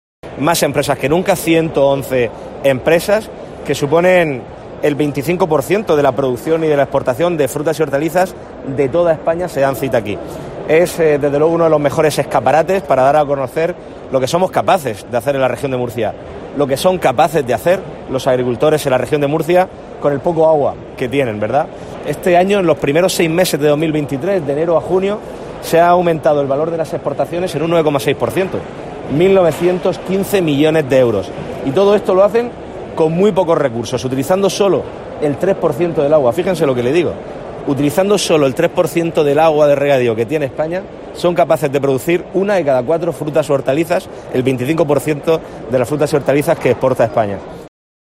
Fernando López Miras, presidente de la Region de Murcia